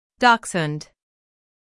IPA: /ˈdɑːks.hʊnd/.
How to Pronounce Dachshund
Syllables: DACHS · hund
dachshund-us.mp3